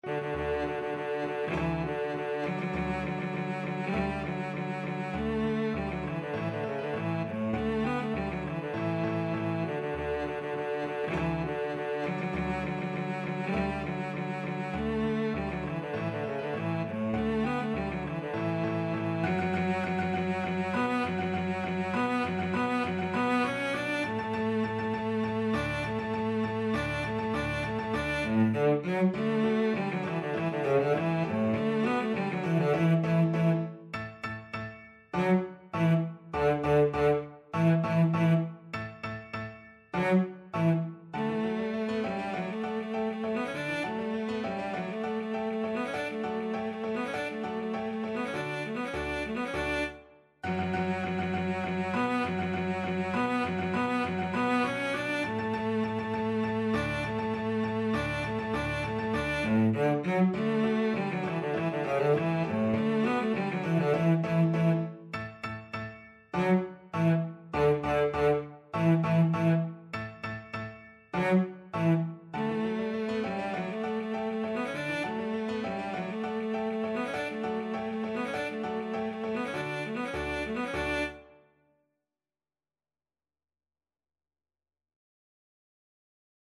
Cello version
2/2 (View more 2/2 Music)
March = c.100